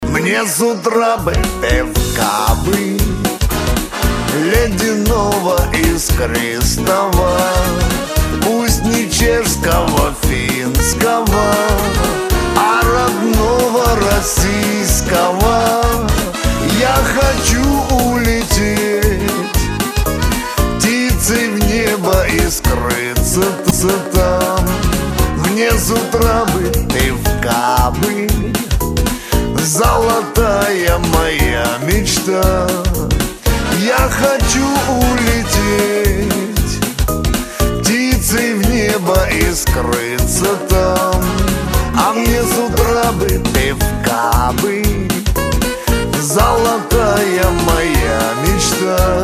из Шансон